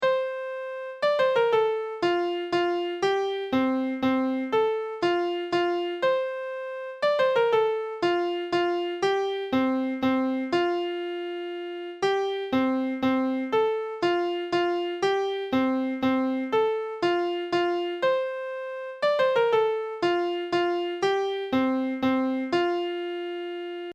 This is a 4 part song. Only the 4th part has a melody.
This song represents the old steam merry-go-round, which, after the engine started, gradually built up speed and noise until the tinny melody was heard above the tud, steam and squeaks of the engine. Music over, the engine slowed down, noise dying away until it stopped completely.
The leader beats 3/4 time, slowly for part one but gradually increasing tempo as each additional part enters.
Part 1: oom-pah-pah. [Engine warms up] Four times on own, but continues throughout.
Part 2: oom-sss-sss. [Steam building up] Four times, then add:
Part 3: oom-twiddly-dee-dee. [Very high squeak] Four times, then add:
Part 4: the melody starts as full speed is reached. This part of the group sings da-da-da with suitable "tinny" sound (eg. hold noses!)
When the music is finished, the merry-go-round slows down, gradually losing its squeak (part 3), then the steam (part 2) until the engine (part 1) finally stops.